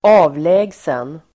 Uttal: [²'a:vlä:gsen]